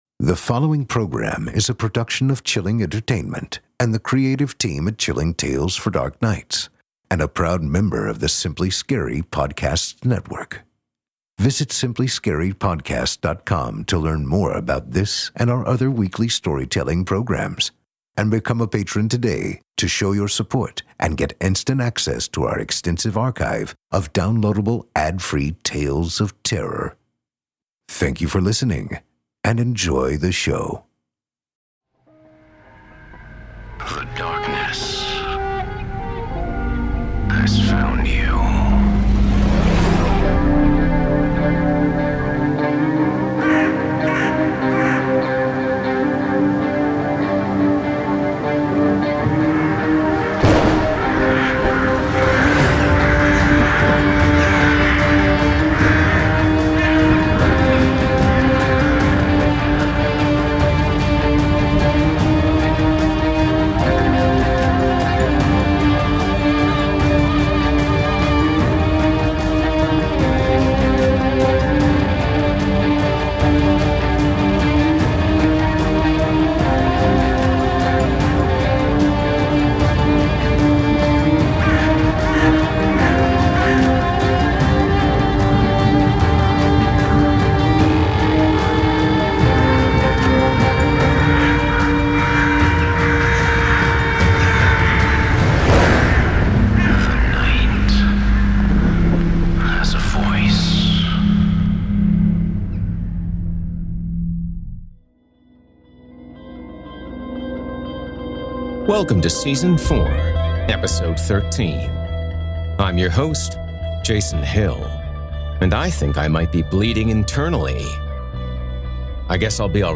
A Horror Anthology and Scary Stories Series Podcast